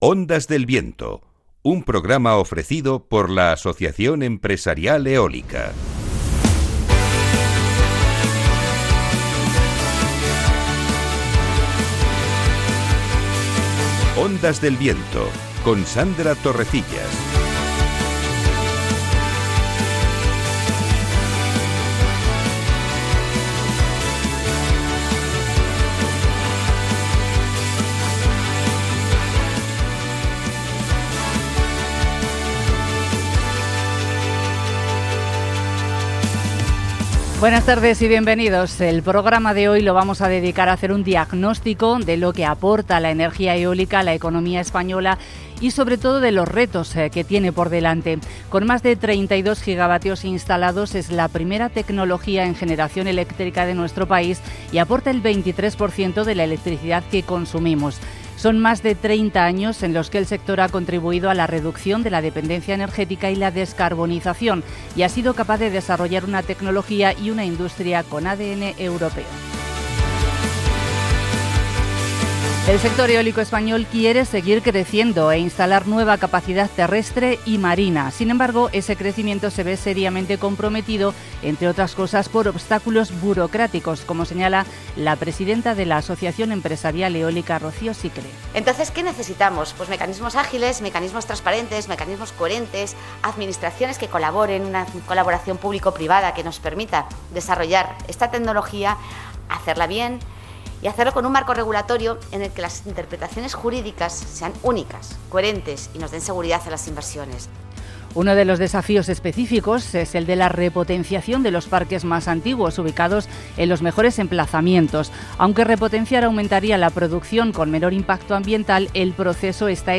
🎙Hoy 7 de octubre hemos emitido un nuevo episodio de Ondas del Viento, el programa radiofónico del sector eólico en la emisora Capital Radio. 🎙Hemos analizado la actualidad del sector eólico con sus retos a futuro y los temas clave y estratégicos.